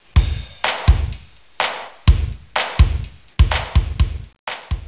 switch.wav